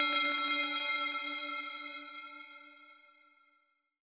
new_tones2.L.wav